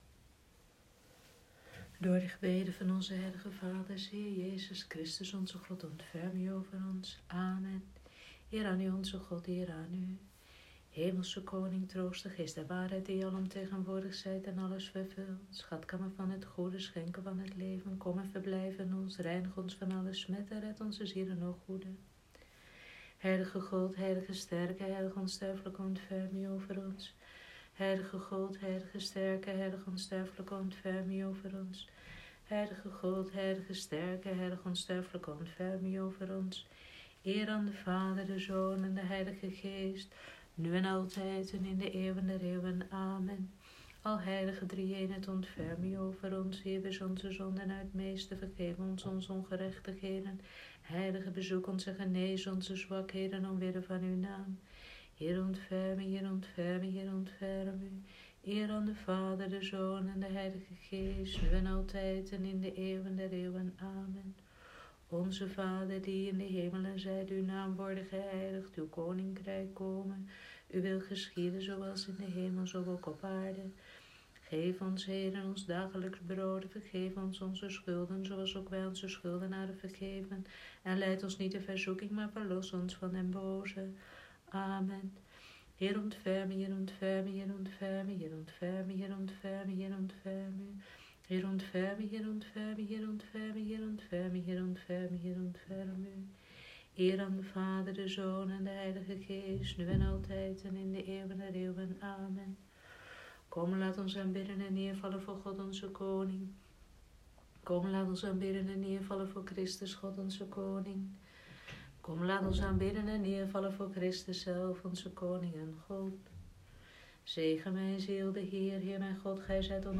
Vespers Vrijdagavond, 10 April 2020
Vespers-vrijdagavond-10-april.m4a